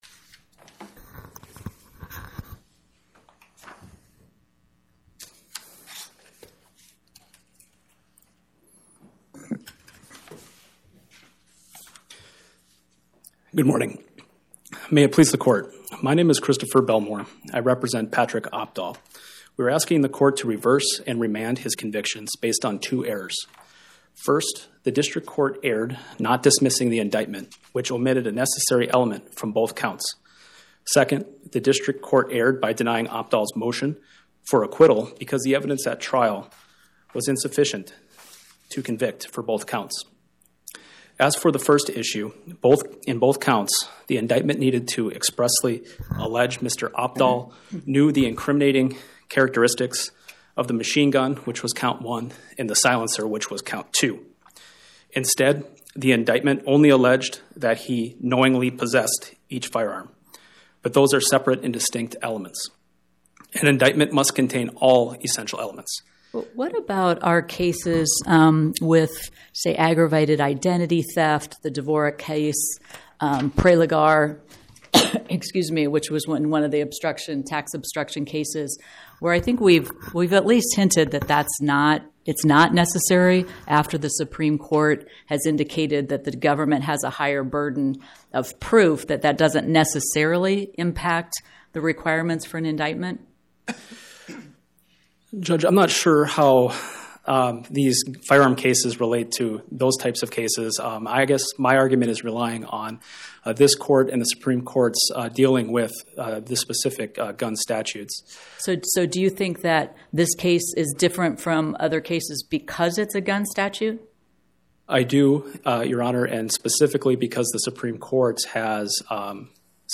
Oral argument argued before the Eighth Circuit U.S. Court of Appeals on or about 10/21/2025